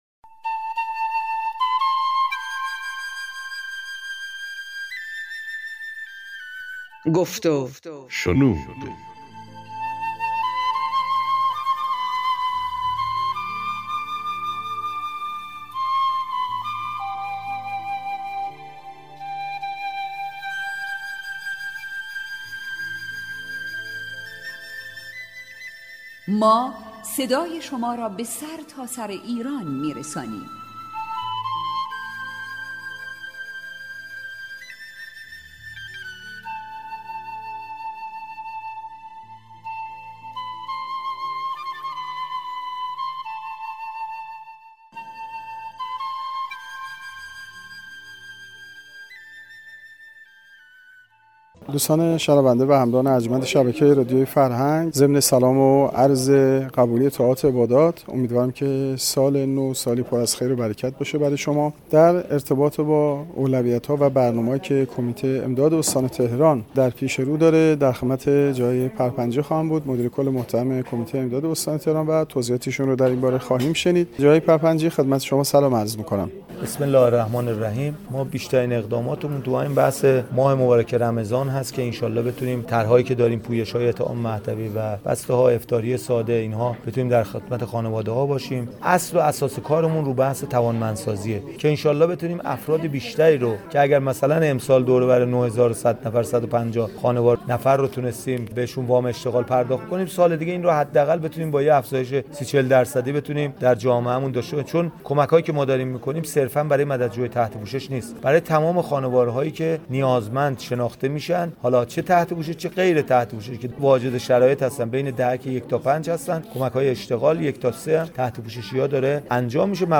گفت و گوی مدیرکل کمیته امداد استان تهران با رادیو فرهنگ برنامه ایستگاه ۱٠۶